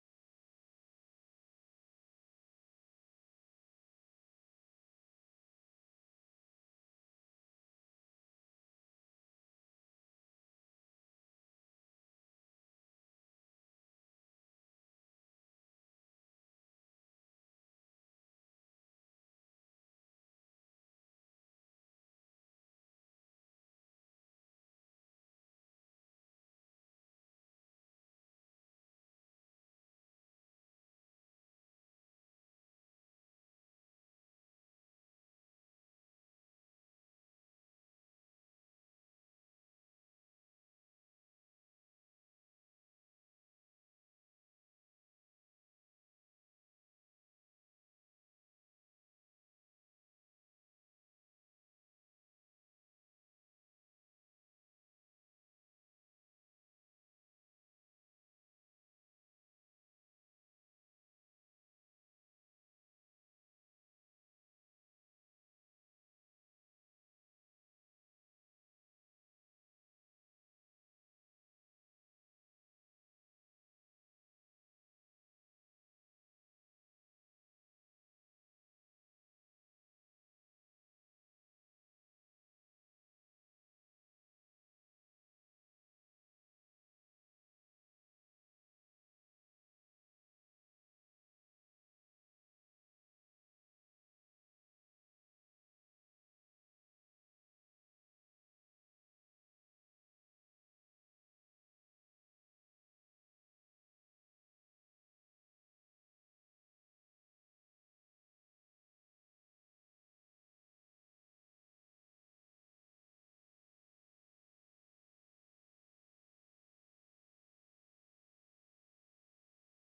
Аудіозапис спільних комітетських слухань 2 грудня 2020 року